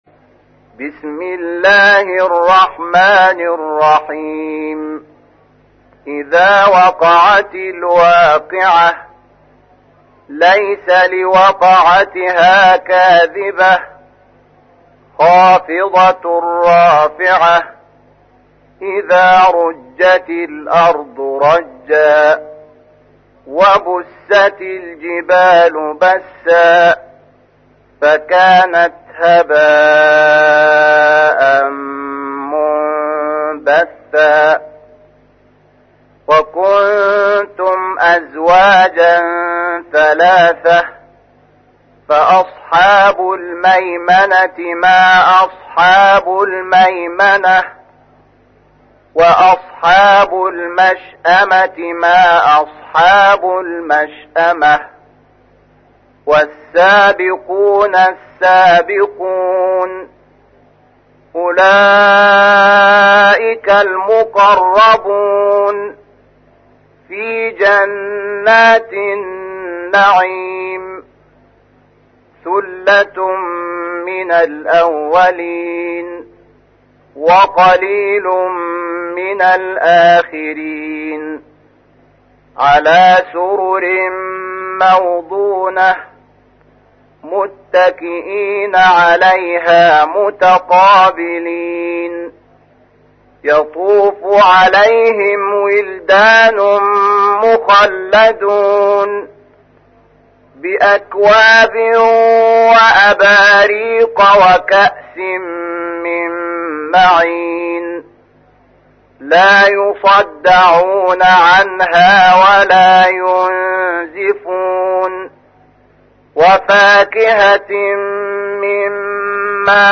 تحميل : 56. سورة الواقعة / القارئ شحات محمد انور / القرآن الكريم / موقع يا حسين